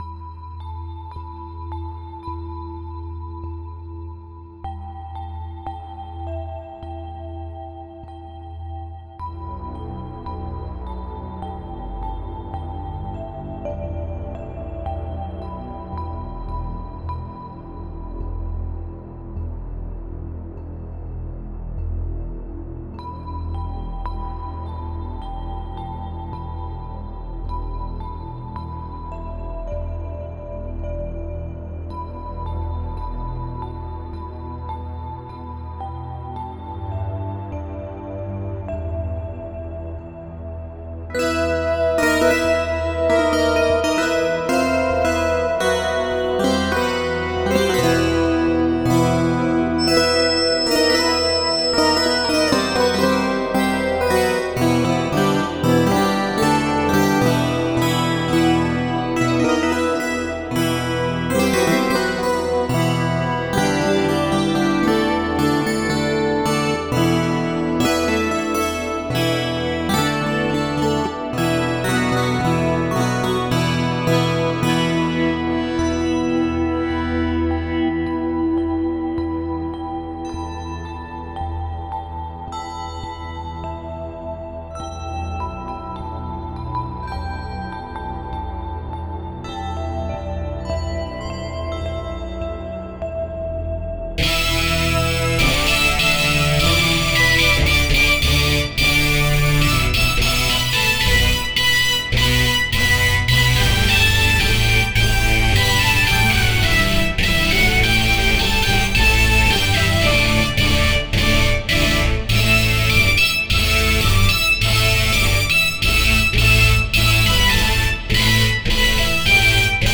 (C) GNU GPL v2 Commentary: My experement with merging soft and hard parts into one song.